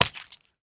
sounds / entity / fish / hurt4.ogg
hurt4.ogg